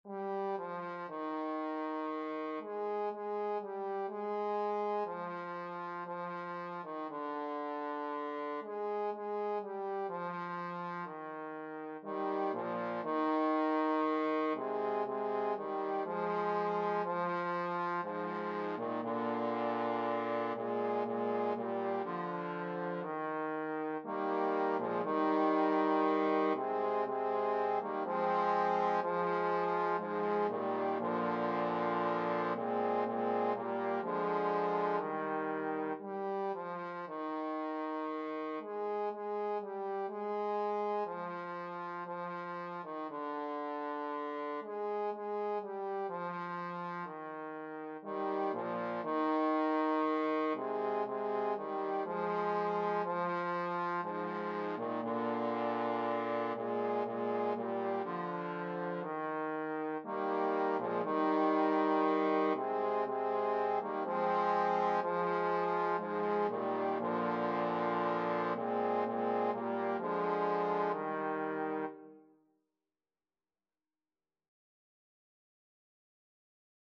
Title: Meu Jesus crucificado II Composer: Anonymous (Traditional) Lyricist: Number of voices: 1v Voicing: Unison Genre: Sacred, Sacred song
Language: Portuguese Instruments: Organ